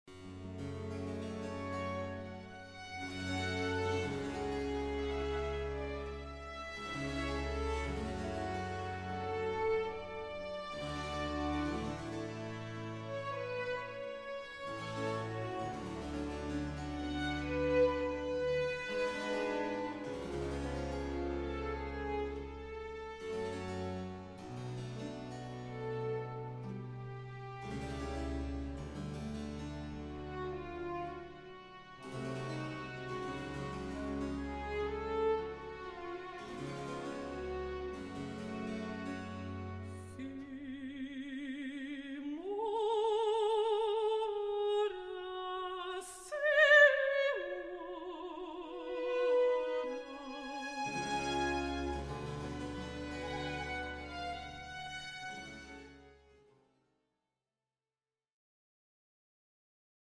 baroque repertoire